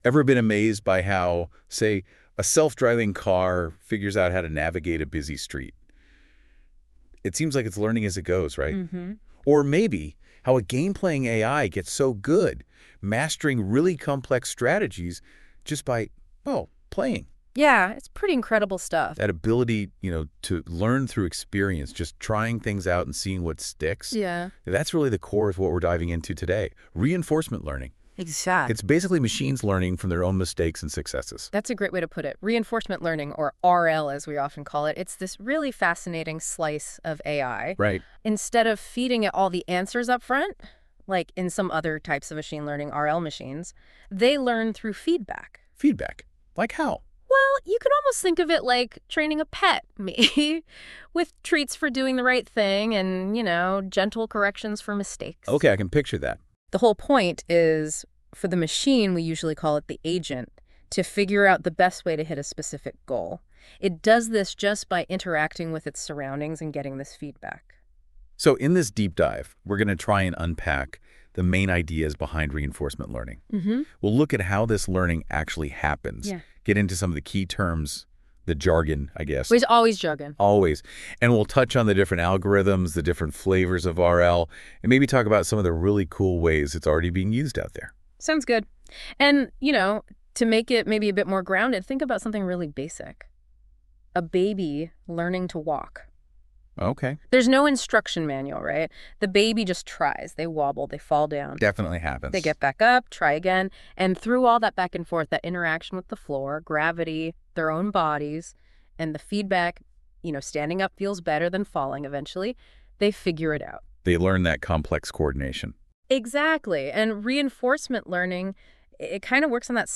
We use Notebook LM to explain latest and important AI papers. Our two hosts explain complex matters in a simple and fun way.